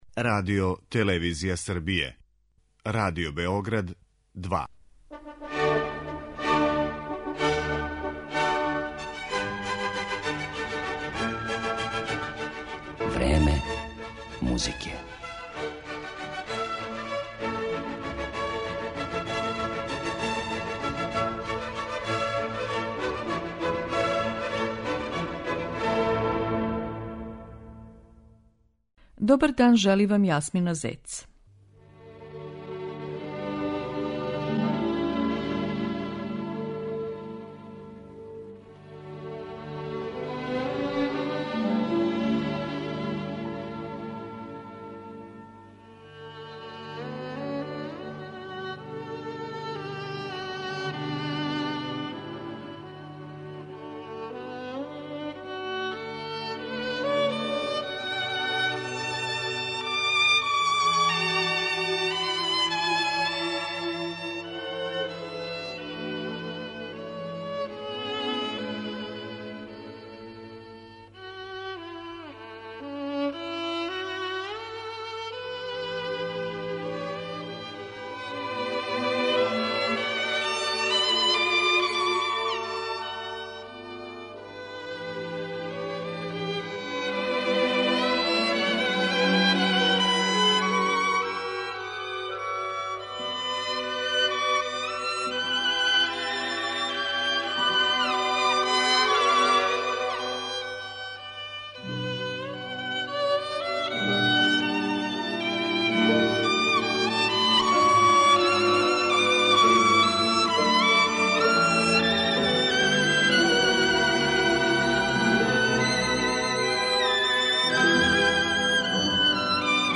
виолинисте